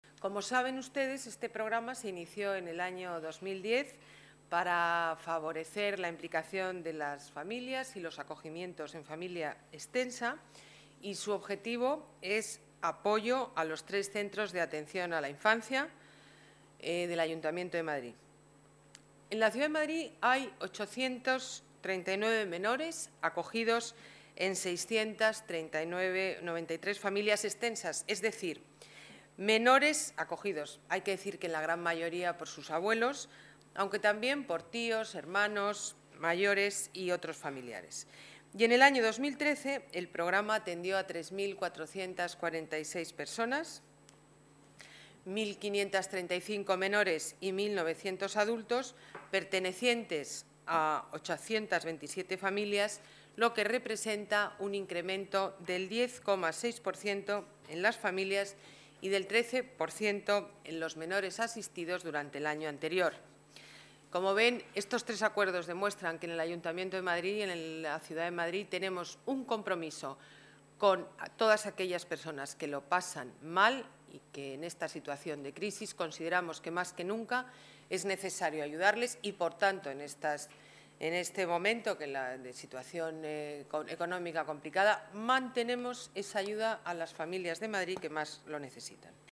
Nueva ventana:Declaraciones alcaldesa Madrid, Ana Botella: Junta Gobierno, más de un millón de euros para protección de menores